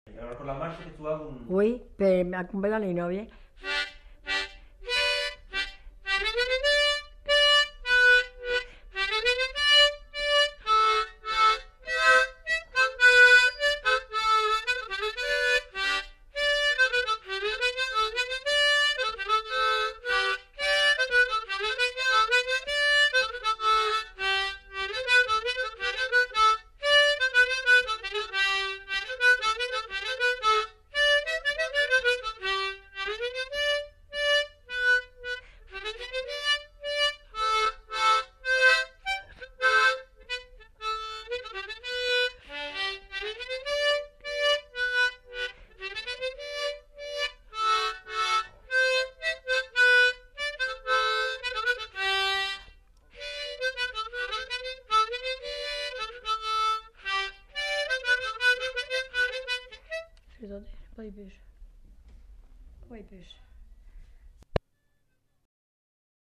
Lieu : Cancon
Genre : morceau instrumental
Instrument de musique : harmonica